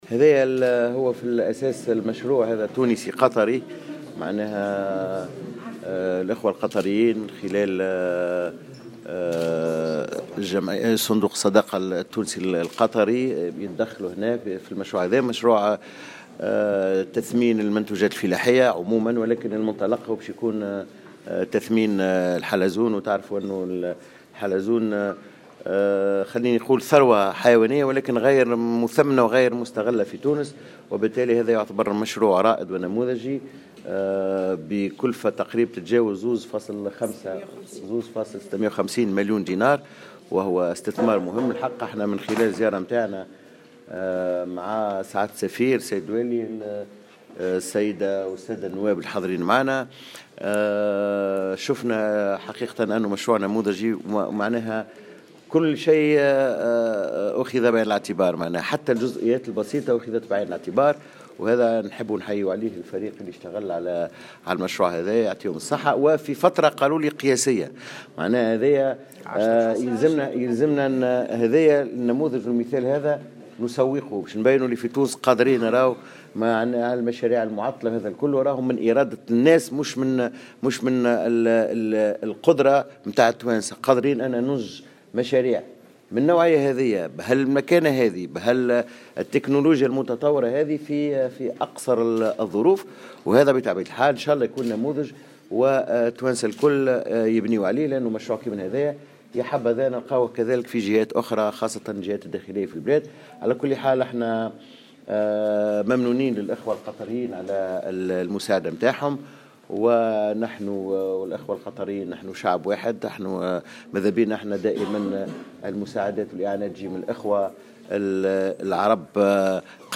وقال في تصريح لمراسل "الجوهرة أف أم"